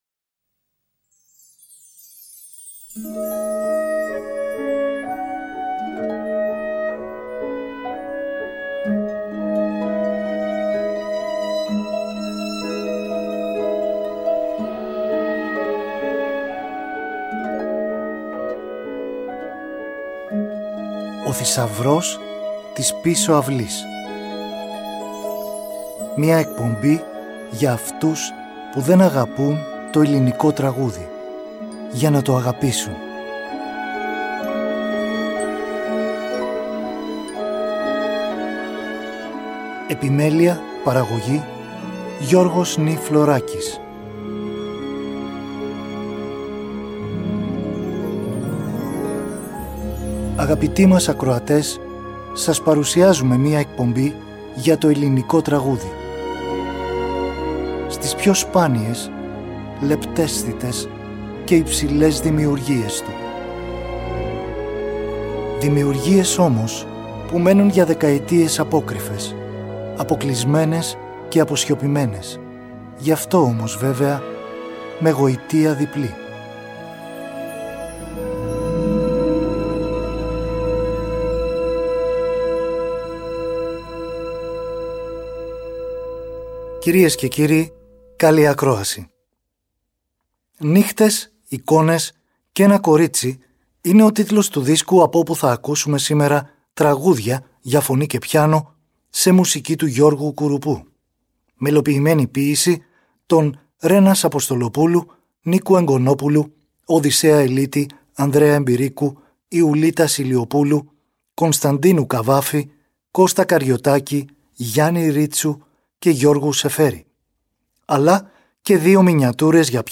τραγούδια για φωνή και πιάνο